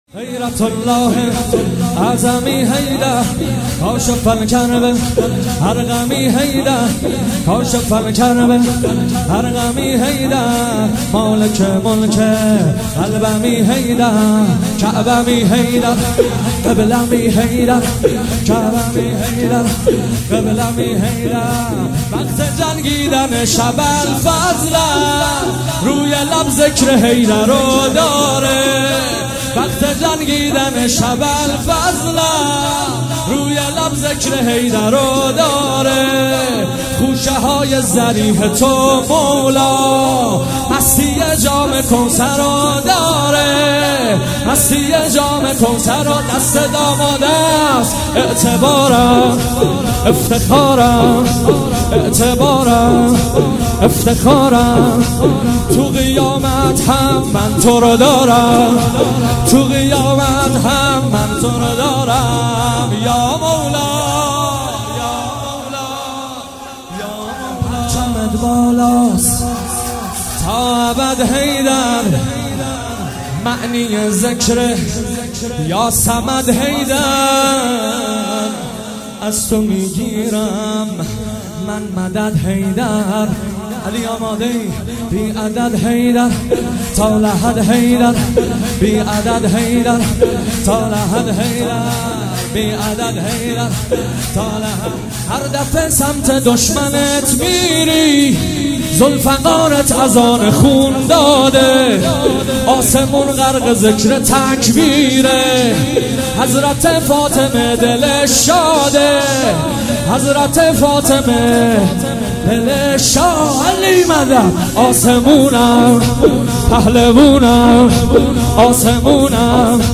وفات حضرت معصومه (س) | مسجد حضرت معصومه(س) کرج